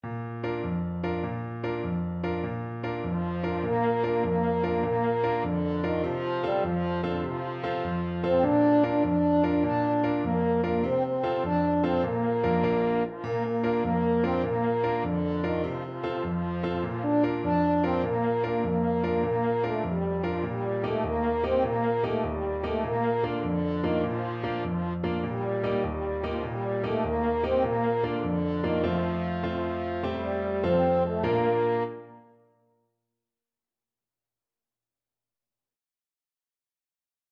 6/8 (View more 6/8 Music)
Allegro .=c.100 (View more music marked Allegro)